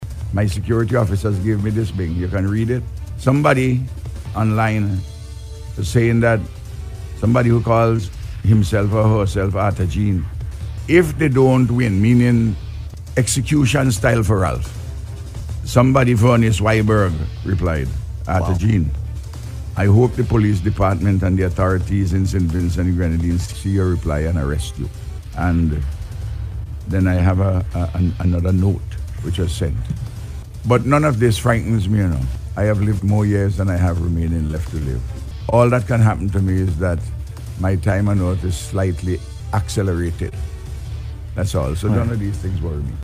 The Prime Minister addressed the issue during his appearance on BOOM FM this morning.